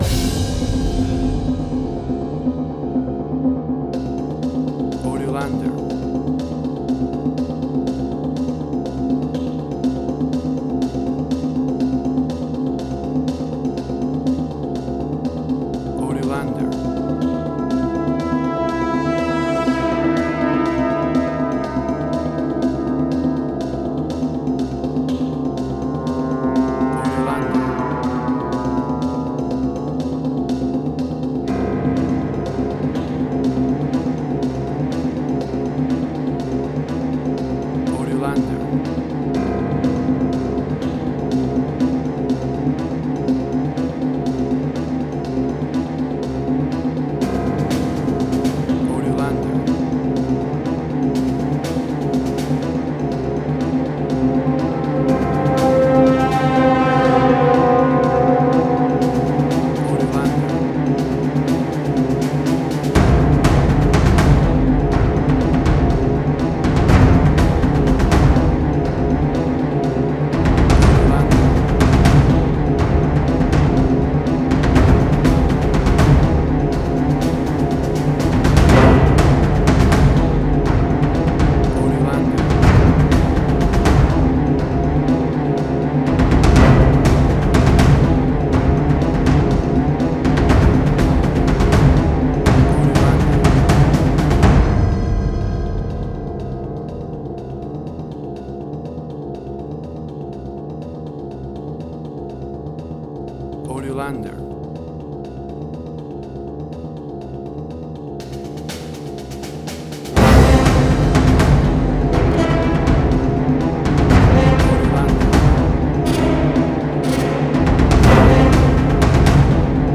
Suspense, Drama, Quirky, Emotional.
Tempo (BPM): 122